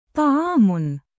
音标：ṭaʿām